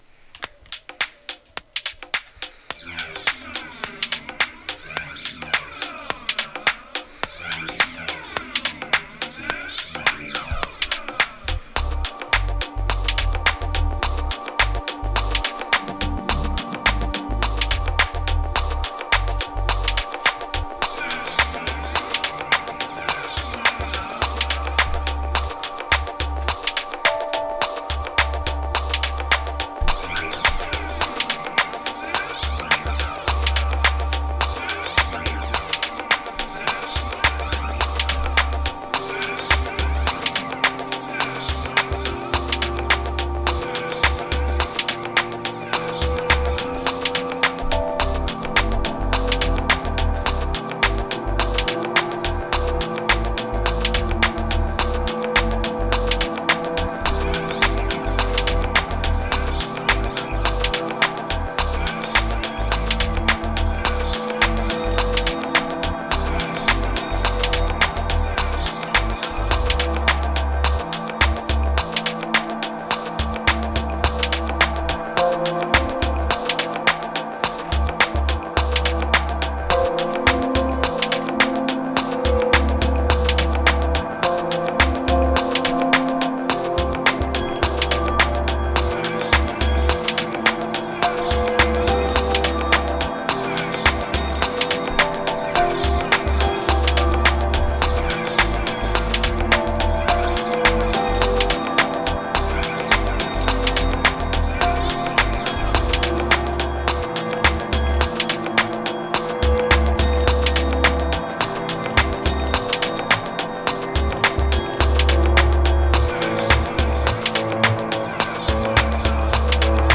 525📈 - 85%🤔 - 53BPM🔊 - 2021-10-15📅 - 396🌟
Kicks Epic Riddim Moaner Attic Seldom Healing Tangled Cosmos